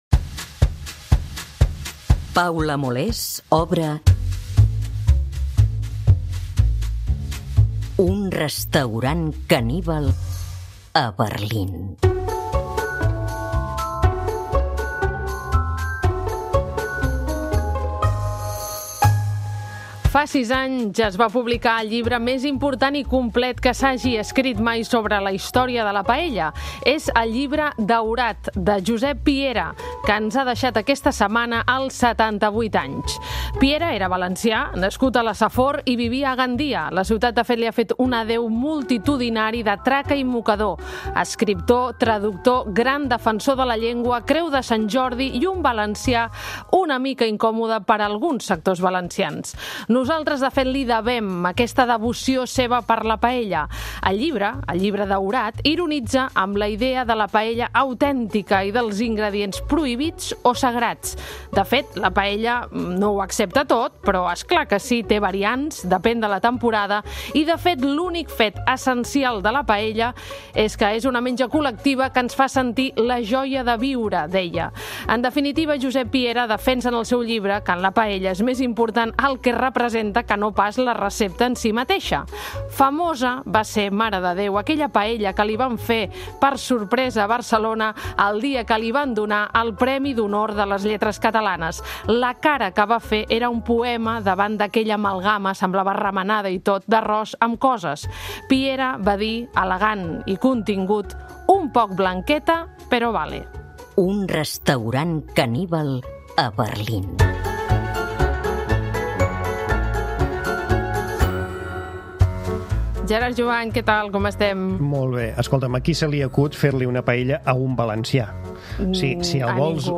A la taula del caníbal, rebem Jancis Robinson, una de les crítiques de vins més influents i respectades del món. Amb ella, conversem sobre per què cada vegada baixa més el consum de vi, sobre les virtuts i els defectes de la beguda per excel·lència, aprenem a tastar un vi sense semblar pretensiosos i ens desmenteix cinc dels mites més repetits sobre el món del vi.